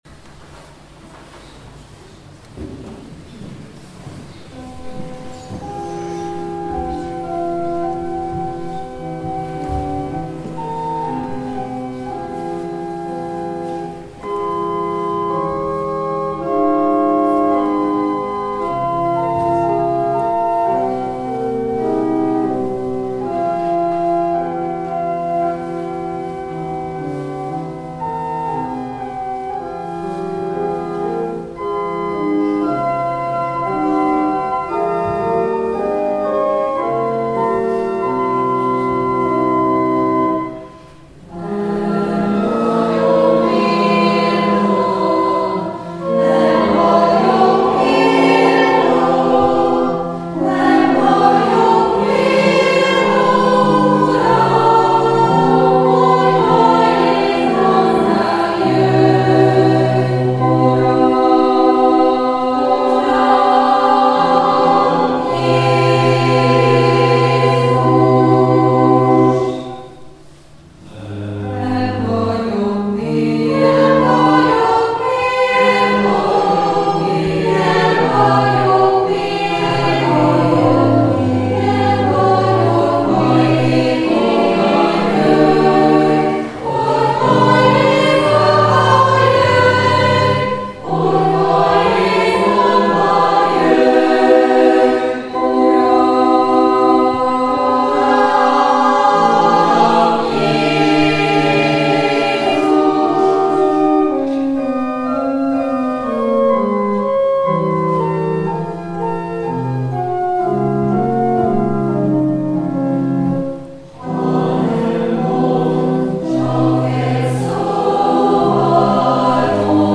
Advent esti áhítat kórusunk szereplésével - Azokat választotta ki Isten, akik a világ szemében erőtlenek, hogy megszégyenítse az erőseket.